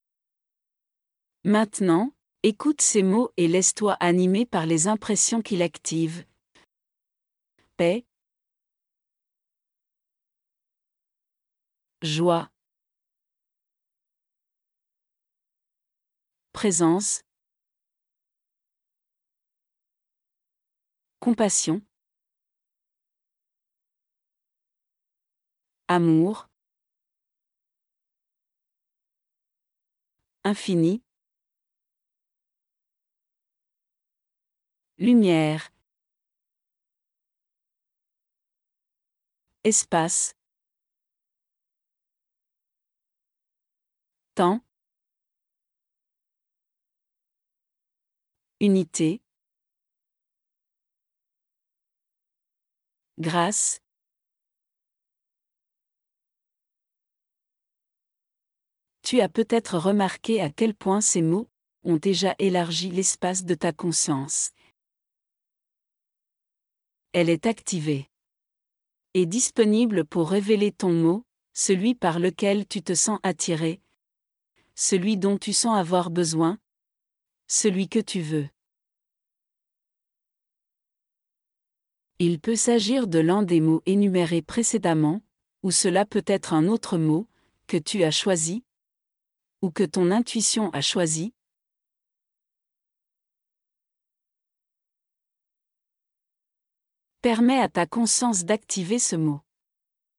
Les indications fournies sont extrêmement délicates, visant uniquement à vous rappeler de maintenir l’état de vigilance et le sens que vous vous êtes fixé au départ.
• Et ici, vous pouvez écouter un fragment de cette méditation, celui qui apparaît immédiatement après l’introduction standard :